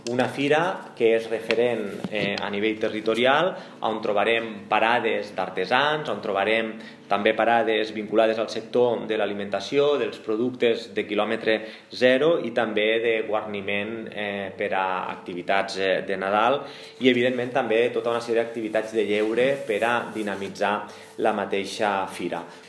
Segons ha destacat l’alcalde de Tortosa, Jordi Jordan, ha manifestat que aquesta és una  fira, plenament consolidada, i un referent per a les Terres de l’Ebre, que contribueix a promocionar tant l’activitat econòmica com les possibilitats de lleure del nucli antic de la ciutat.